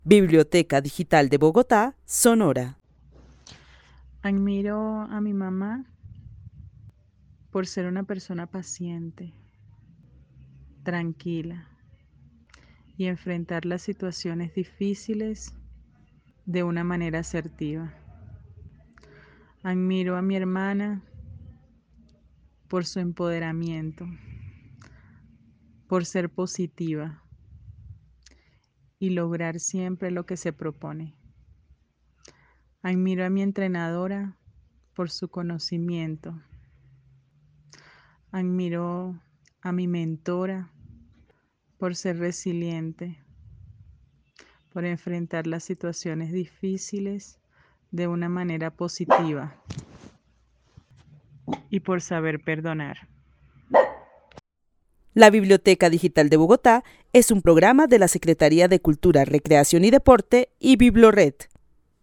Narración oral de una mujer que vive en la ciudad de Bogotá, quien admira a su mamá por ser una persona paciente, tranquila y capaz de enfrentar las situaciones difíciles de una manera acertada. También admira a su hermana por su empoderamiento; a su entrenadora por el conocimiento que tiene y a su mentora por ser resiliente y enfrentar las situaciones difíciles de una manera positiva y saber perdonar. El testimonio fue recolectado en el marco del laboratorio de co-creación "Postales sonoras: mujeres escuchando mujeres" de la línea Cultura Digital e Innovación de la Red Distrital de Bibliotecas Públicas de Bogotá - BibloRed.